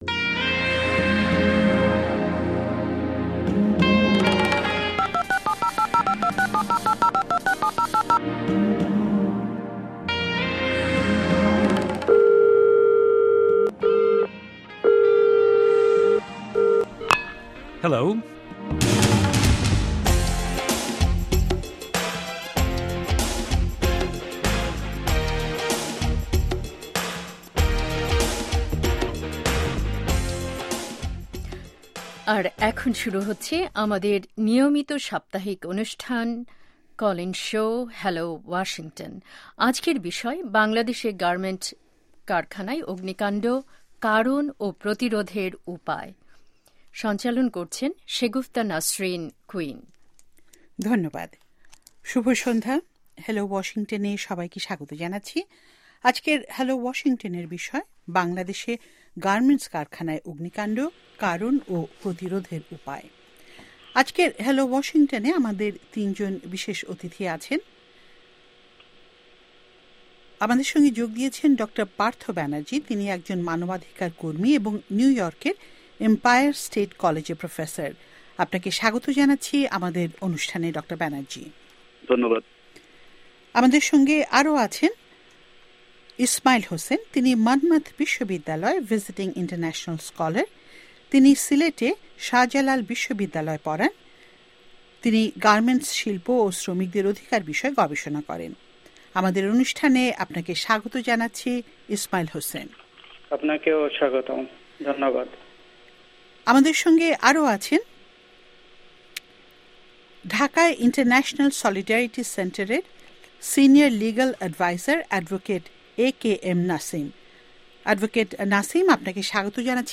আজকের হ্যালো ওয়াশিংটনের বিষয় ছিল বাংলাদেশে গার্মেন্টস কারখানায় অগ্নিকান্ড: কারণ ও প্রতিরোধের উপায়। আজকের হ্যালো ওয়াশিংটনে এ আমাদের তিনজন বিশেষ অতিথি ছিলেন।